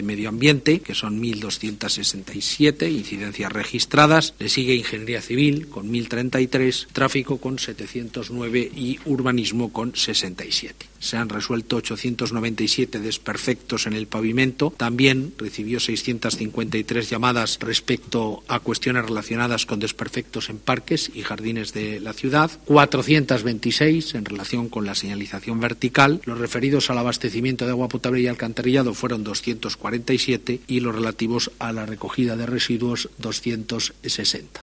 El concejal Fernando Rodríguez informa sobre el funcionamiento de Salamanca Avisa